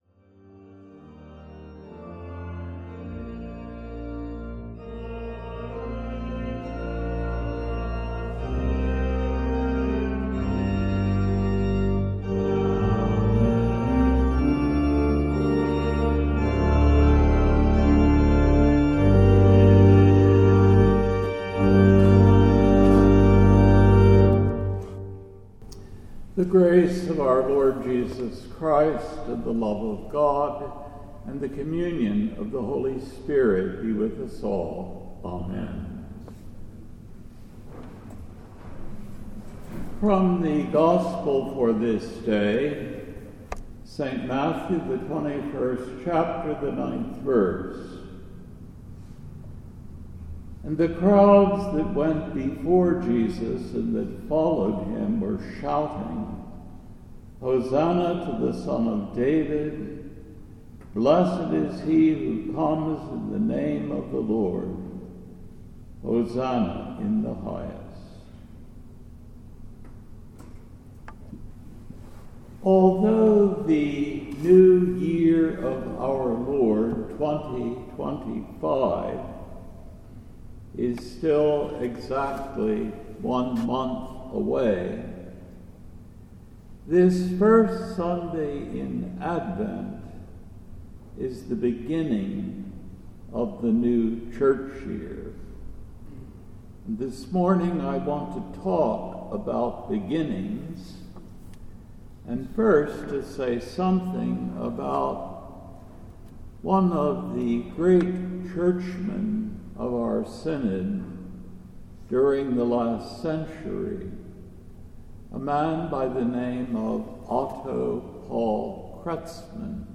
The First Sunday in Advent December 1, 2024 AD